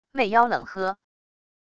魅妖冷喝wav音频